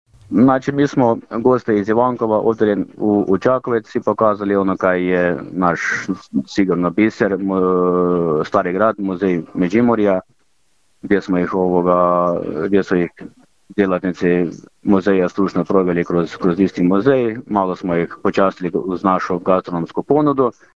Manifestacija je to koja obogaćuje turističku ponudu stoga je i ovog puta podržana od strane Općine Domašinec, kaže načelnik Mario Tomašek.